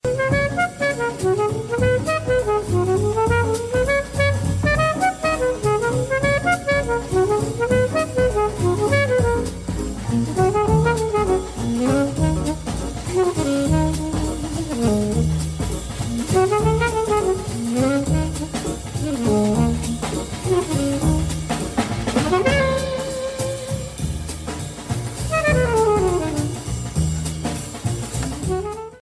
Jazz Ringtones